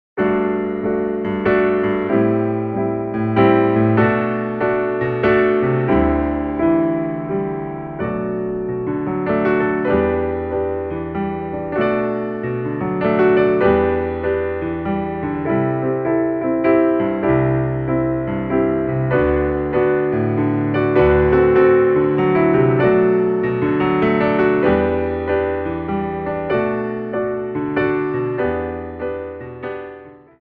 33 Inspirational Ballet Class Tracks
Slow Tendus
3/4 (16x8)